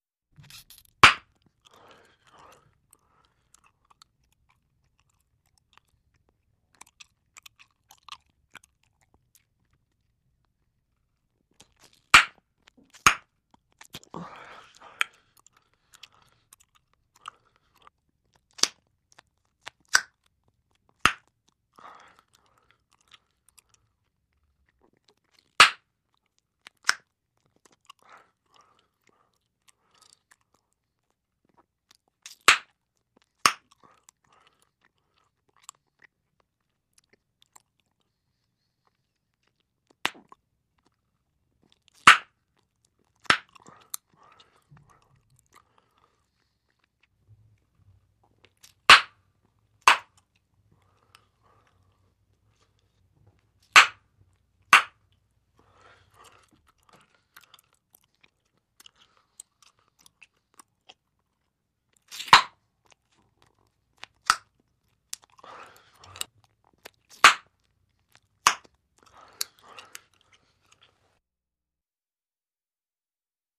Gum popping, chewing and snapping